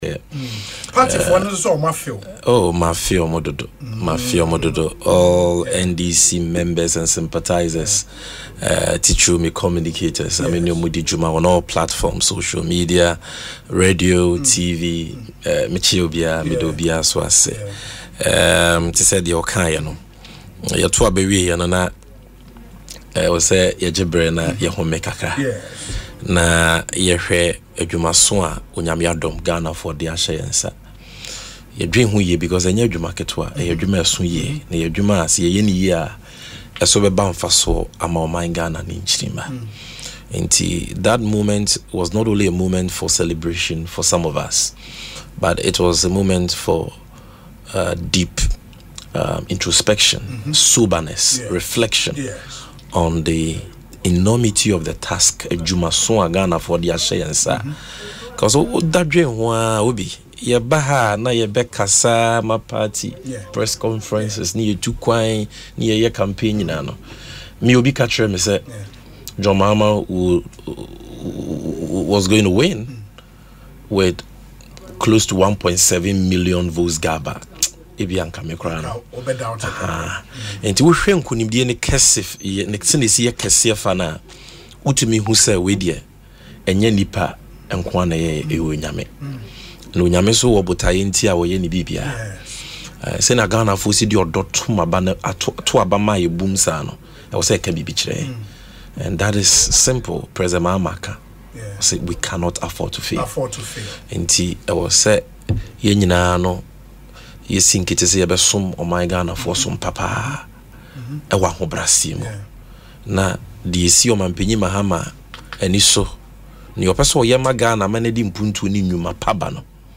In an interview on Asempa FM’s Ekosii Sen, Gyamfi, who is currently the Acting Managing Director of the Precious Minerals Marketing Company (PMMC), revealed that the NDC is fully aware of the responsibility entrusted to them.